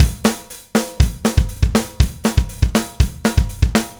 Power Pop Punk Drums Intro.wav